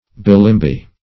Bilimbi \Bi*lim"bi\, Bilimbing \Bi*lim"bing\, n. [Malay.]